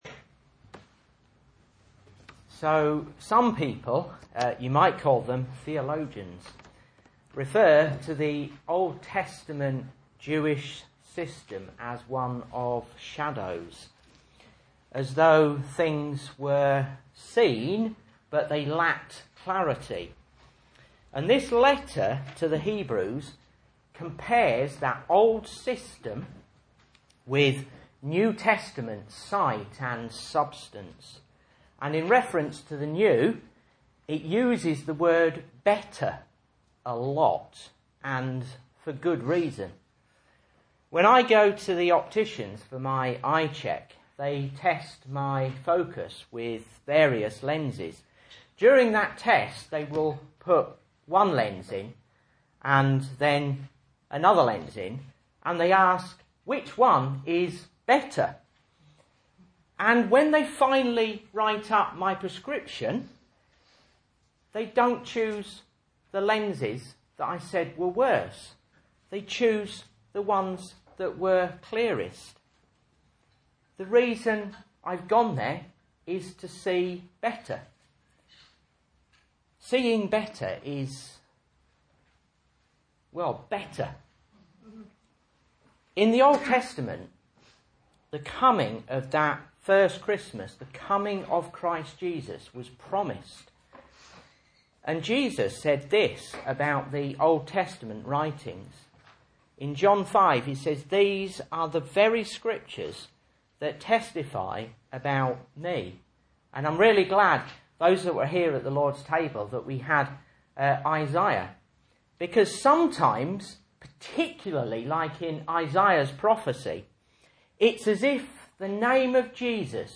Message Scripture: Hebrews 11:39-40 | Listen